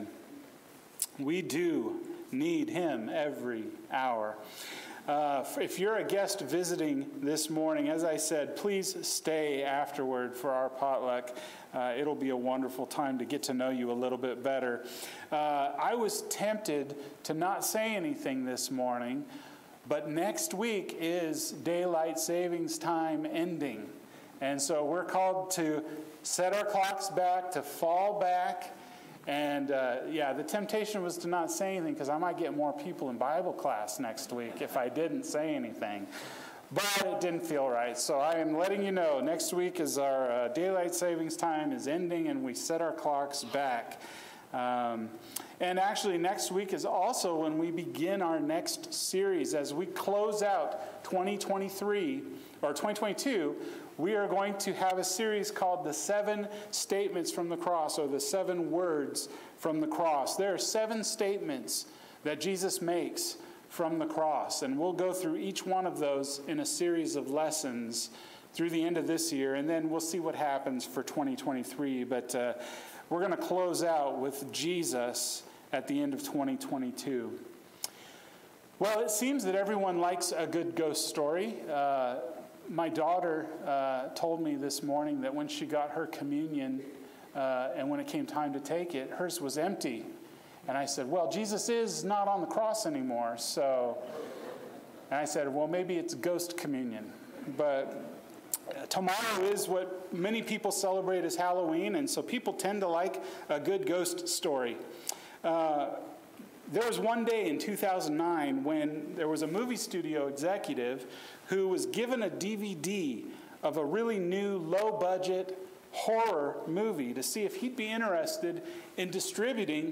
Fear Not – Isaiah 41:10 – Sermon